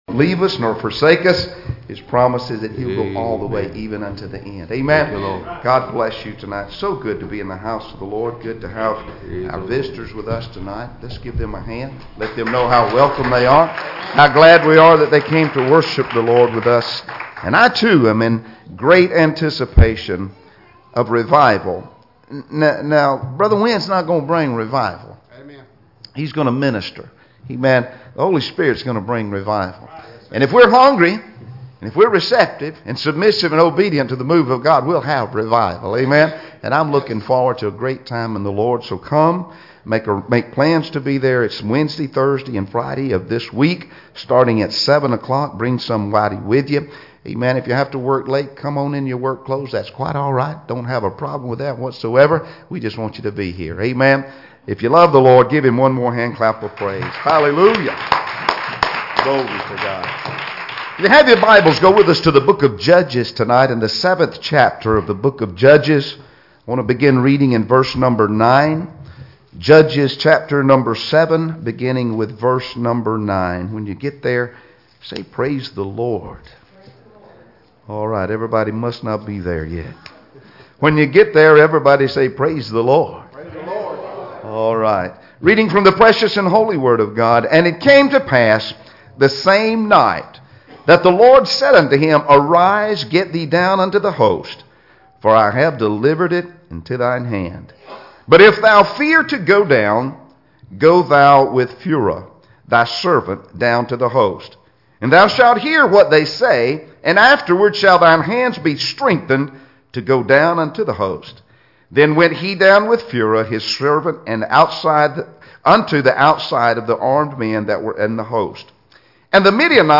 Passage: Judges 7:9 Service Type: Sunday Evening Services